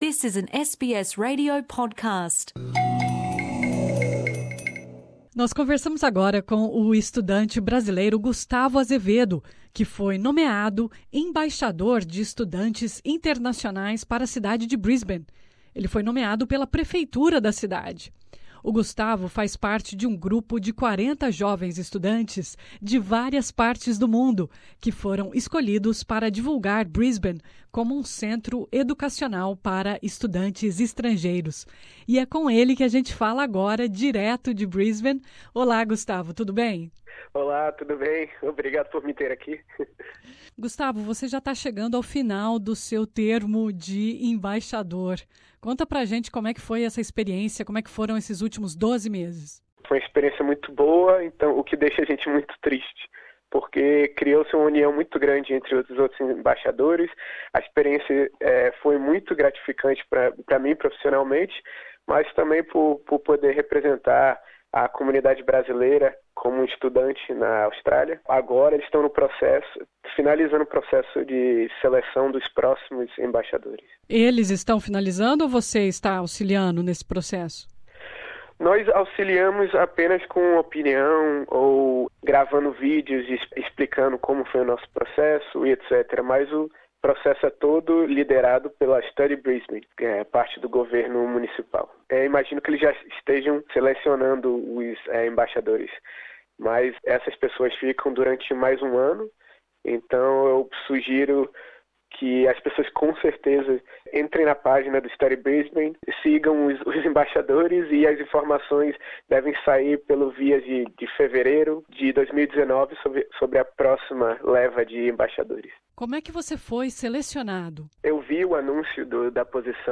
Nessa entrevista